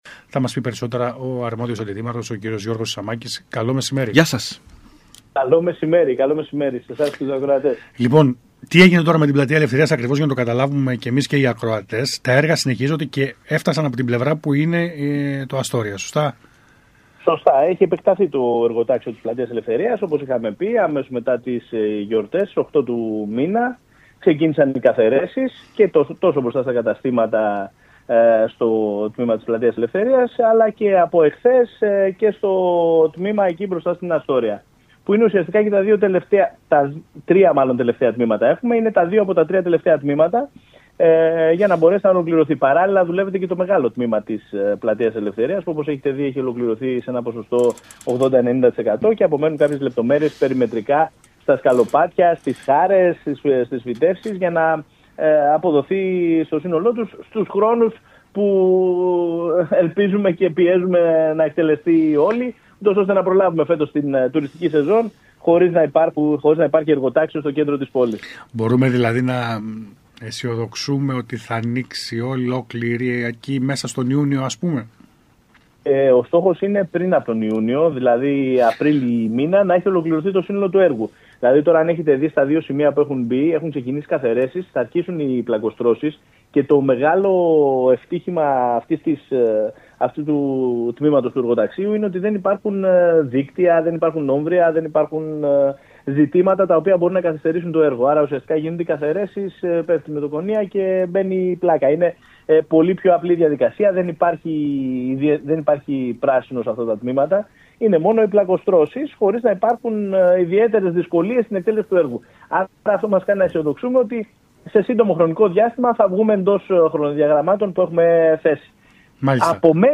ΚΡΗΤΗ - Για τα μεγάλα έργα που βρίσκονται σε εξέλιξη στο Ηράκλειο μίλησε ο Αντιδήμαρχος Τεχνικών Έργων Γιώργος Σισαμάκης το μεσημέρι της Τετάρτης 22 Ιανουαρίου,